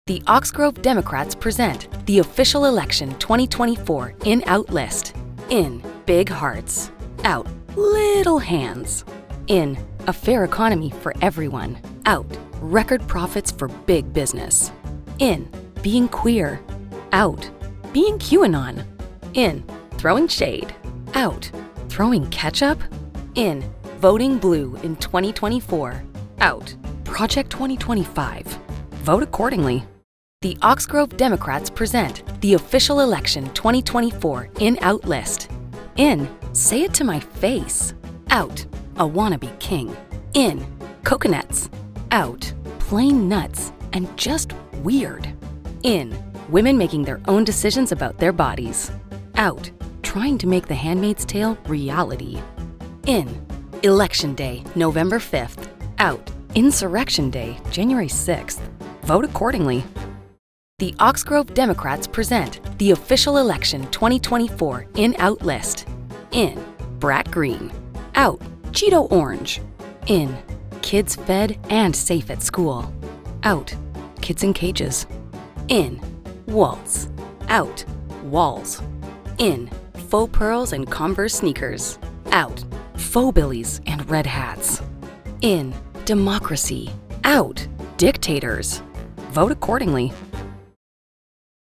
Female
Democratic Spots
Campaign X 3, Cheeky, Quirky